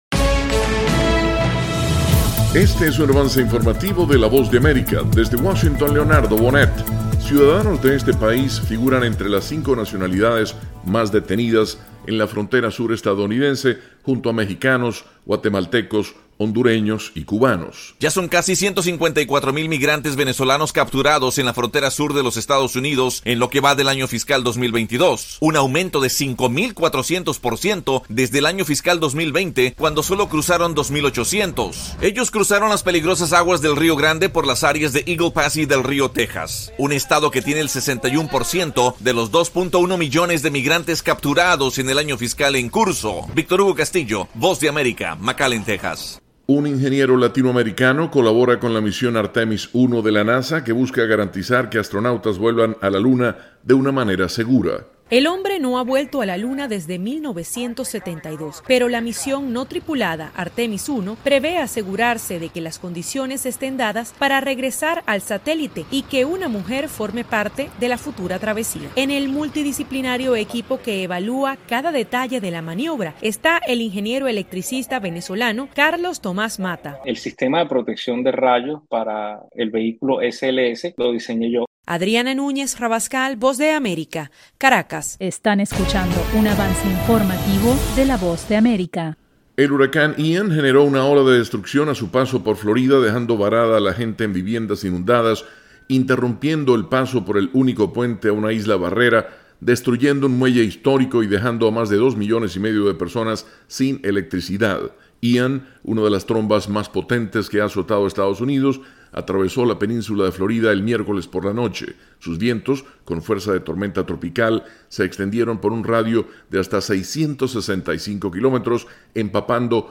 Avance Informativo 3:00 PM
El siguiente es un avance informativo presentado por la Voz de América, desde Washington